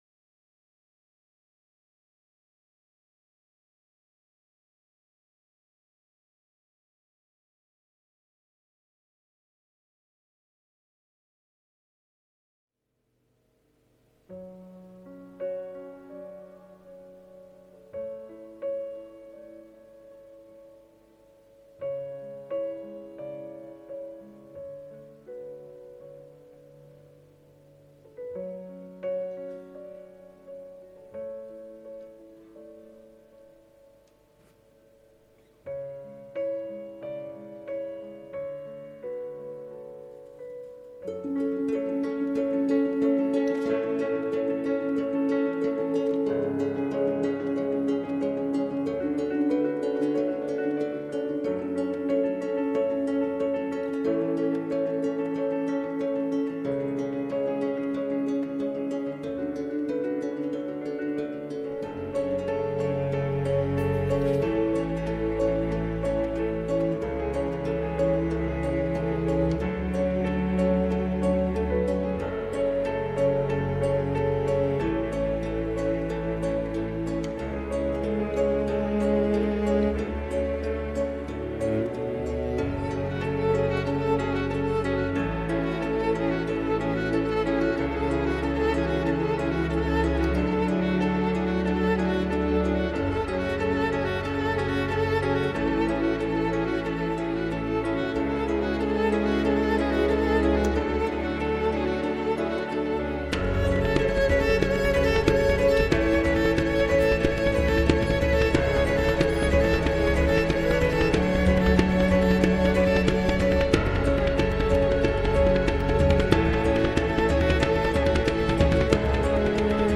Neo-Classical